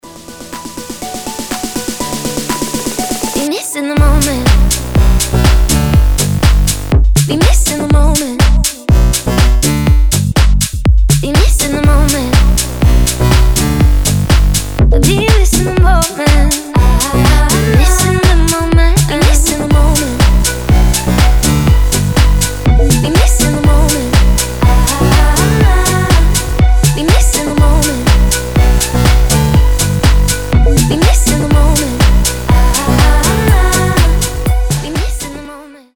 • Качество: 320, Stereo
deep house
Electronic
EDM
басы
Tech House
красивый женский голос